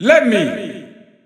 Announcer pronouncing Lemmy in Italian.
Lemmy_Italian_Announcer_SSBU.wav